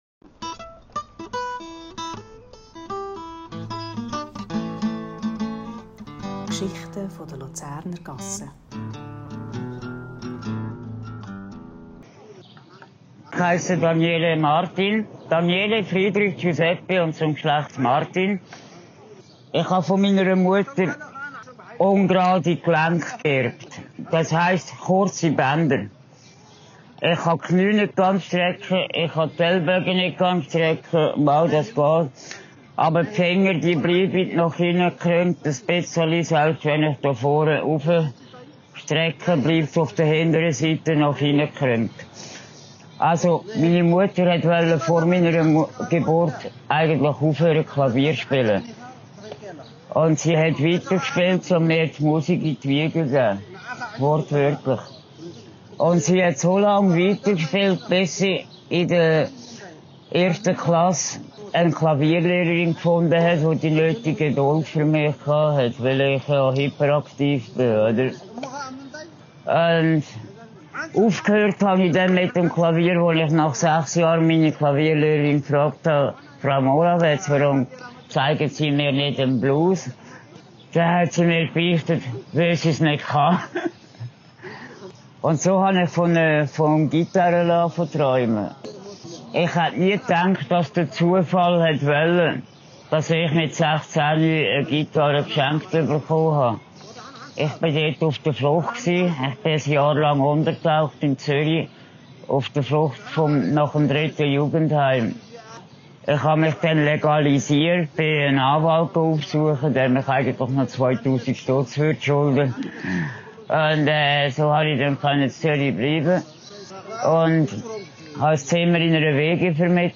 / aufgenommen am 23. Juni 2025 im Vögeligärtli.